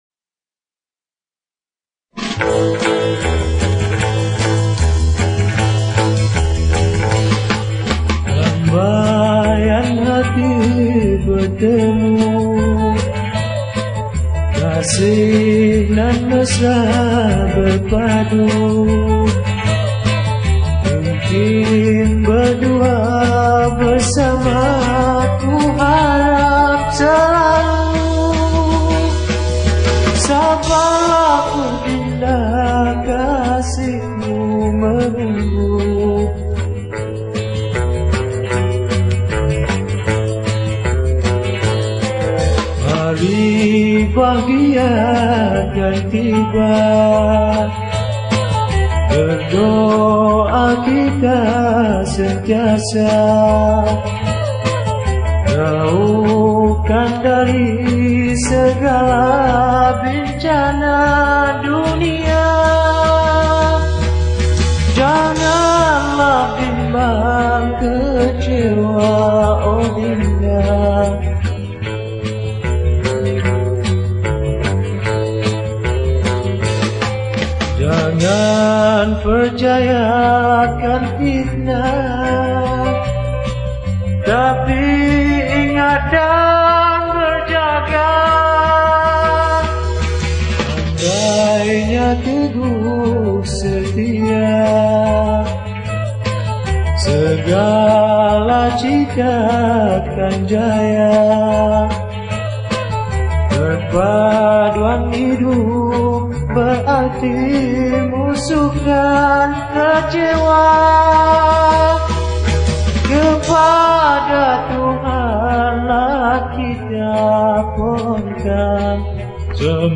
Pop Yeh Yeh
Malay Song